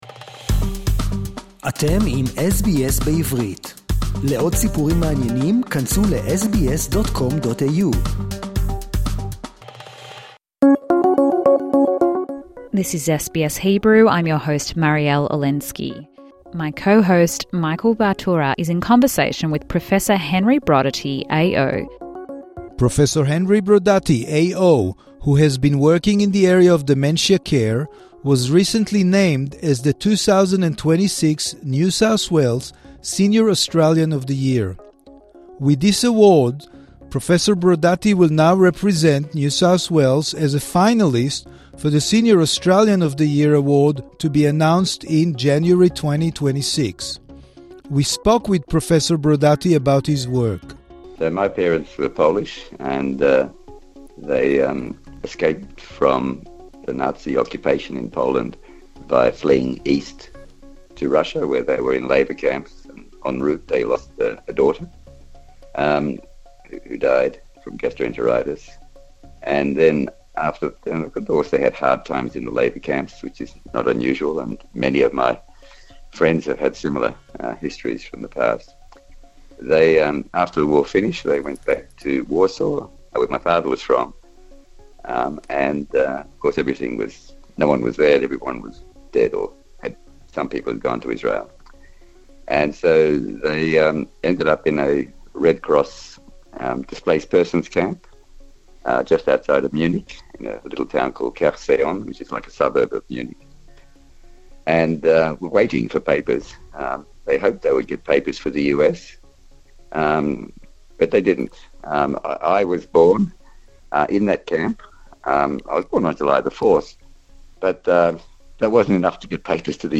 Interview with Professor Henry Brodaty OA - NSW Senior Australian of the Year